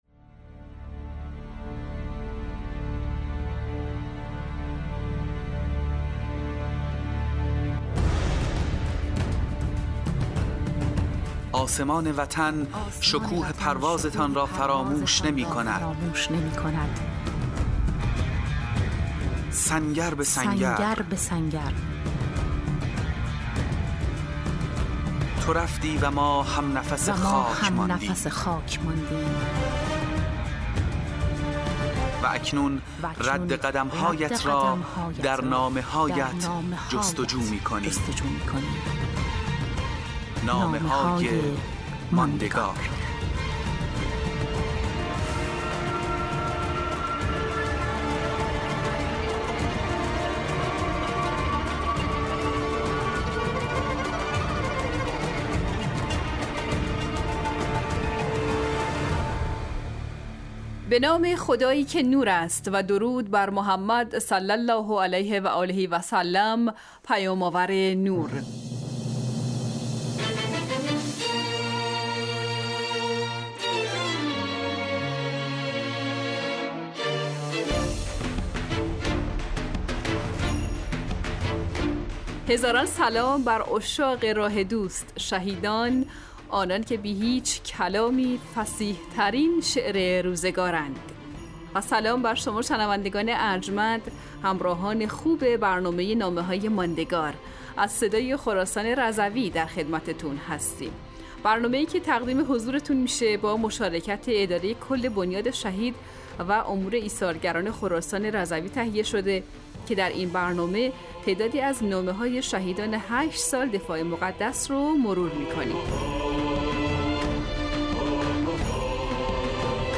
مجموعه 25 برنامه رادیویی از شهدای استان خراسان رضوی ( سری دوم ) - شماره 10